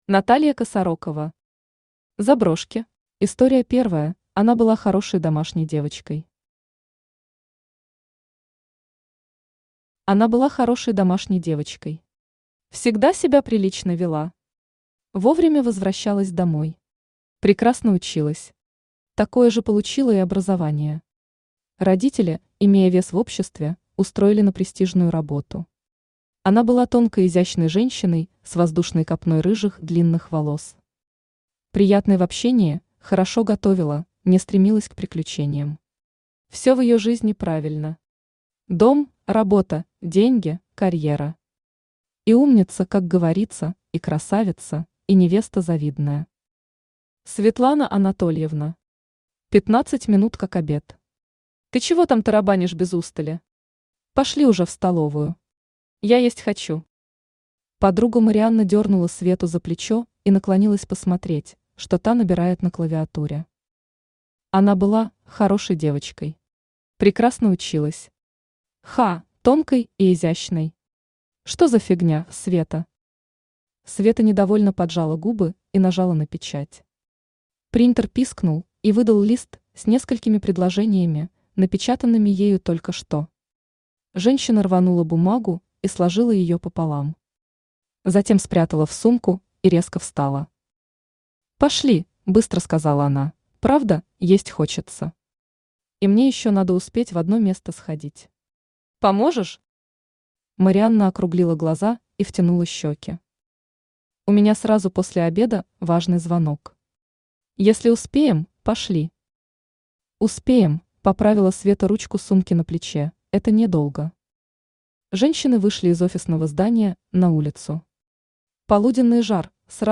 Аудиокнига Заброшки | Библиотека аудиокниг
Aудиокнига Заброшки Автор Наталья Владимировна Косарокова Читает аудиокнигу Авточтец ЛитРес.